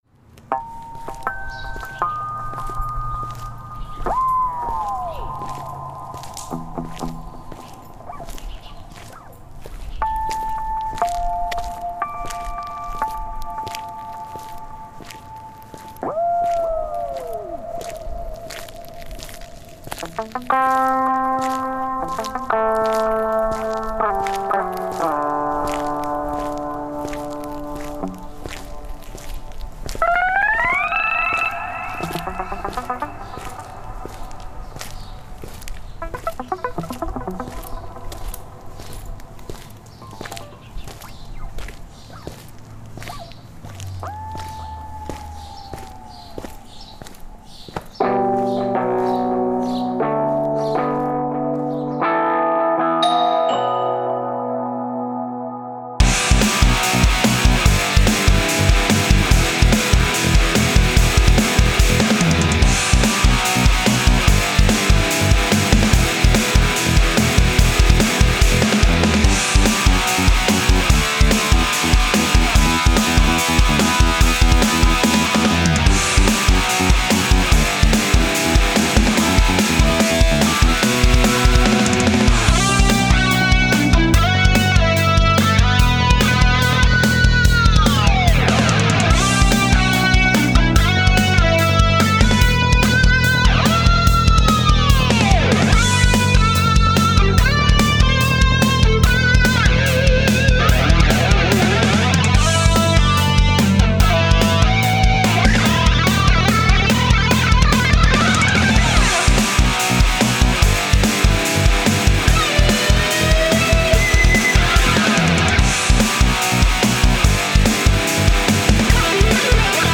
Файл в обменнике2 Myзыкa->Зарубежный рок
Жанры: Metal